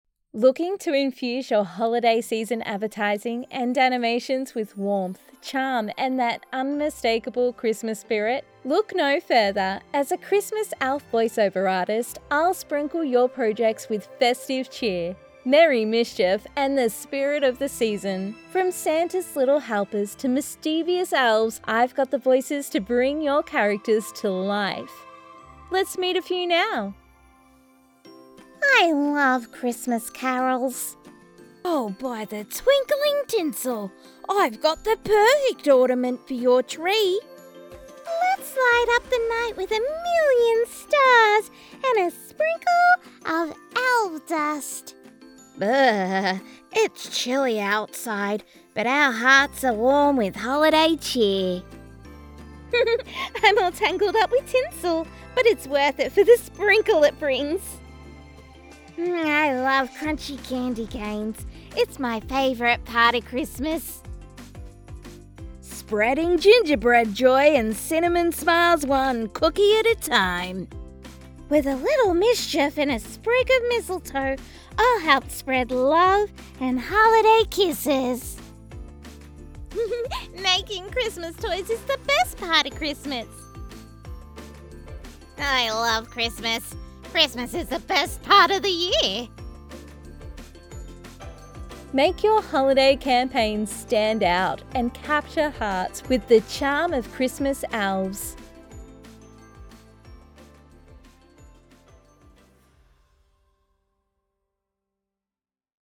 Female
Warm , versatile , dynamic and engaging . Large vocal range.
Audiobooks
Selection Of Audiobook Narration